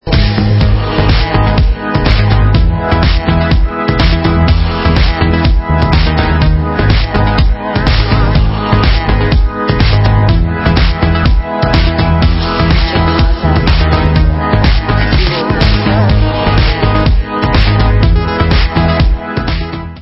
sledovat novinky v kategorii Dance
Pop